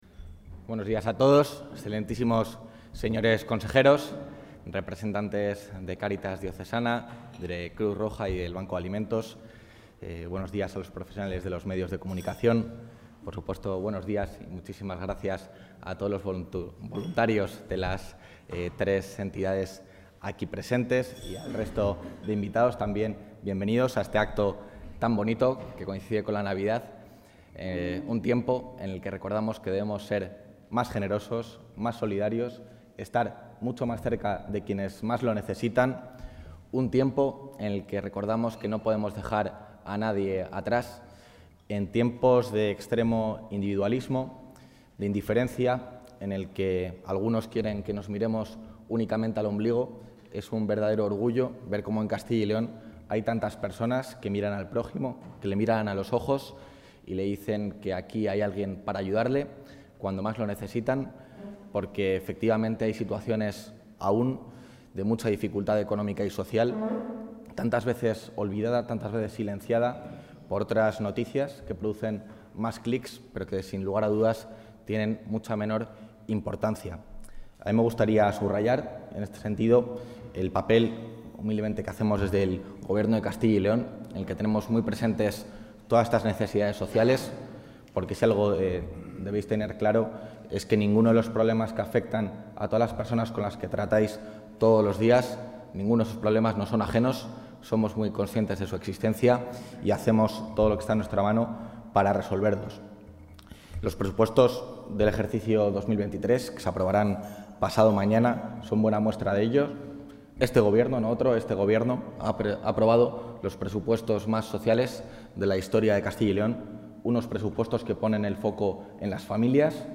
Intervención del vicepresidente.
El vicepresidente de la Junta de Castilla y León, Juan García-Gallardo, ha presidido esta mañana en el convento de los Agustinos Filipinos de Valladolid el acto en el que Tierra de Sabor, la marca de calidad agroalimentaria amparada por la Junta de Castilla y León, ha entregado la donación de 225.000 euros a la Federación del Banco de Alimentos, Cáritas y Cruz Roja para ayudar a las personas que peor lo están pasando durante estas Navidades.